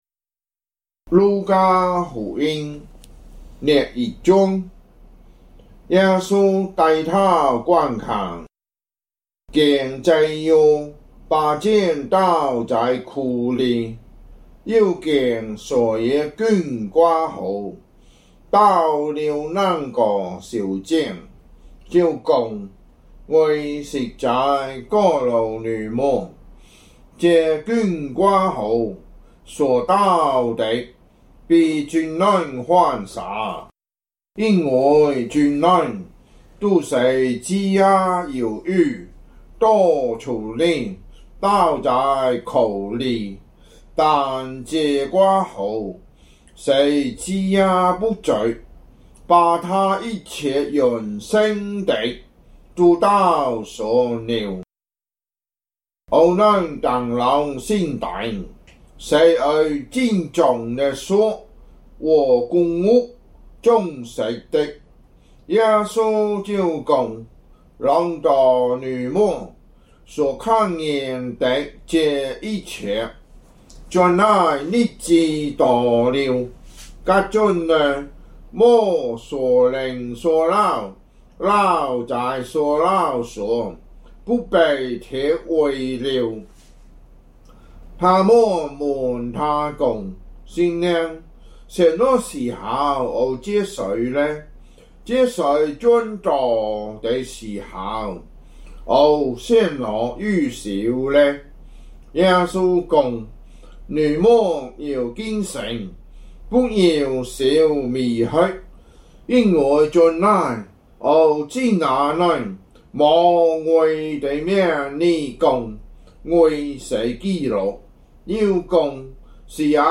福州話有聲聖經 路加福音 21章